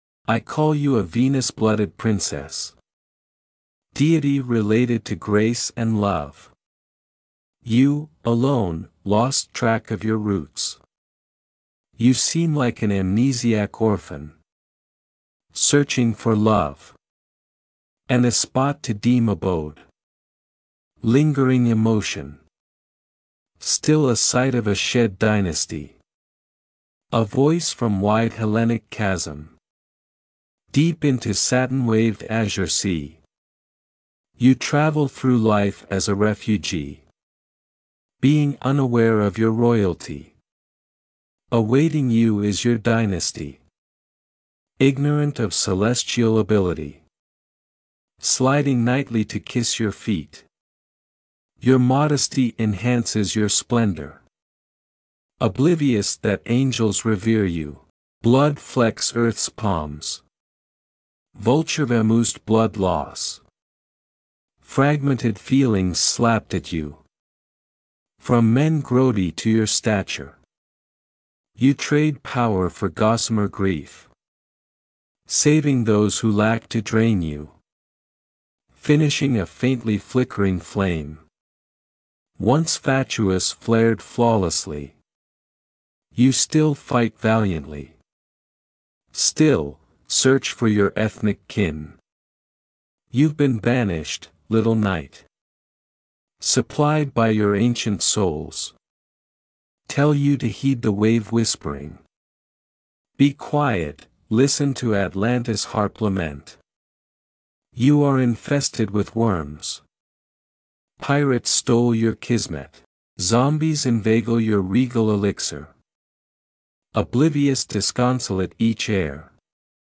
You have an excellent voice for narration.